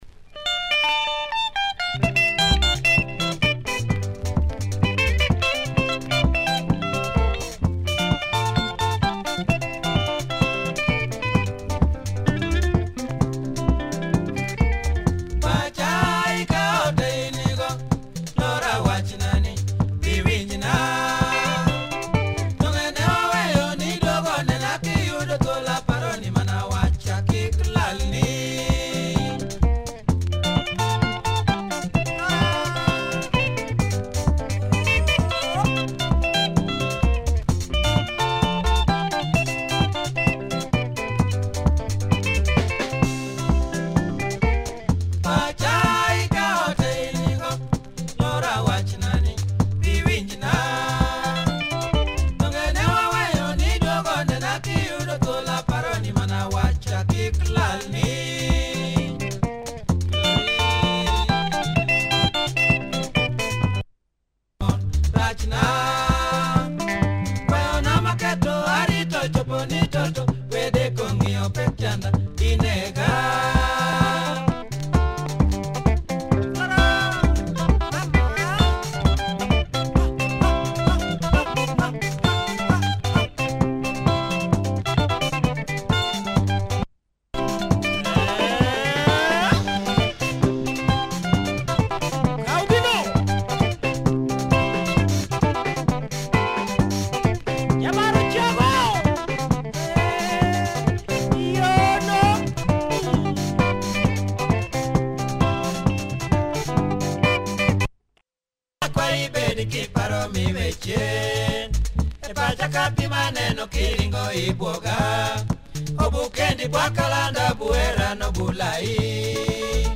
Bumpin’ Luo Benga